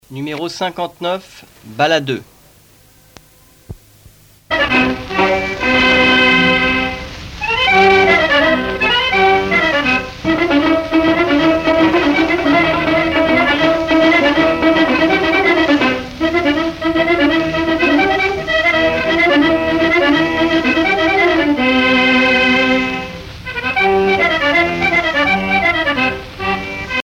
danse : bal à deux
Pièce musicale éditée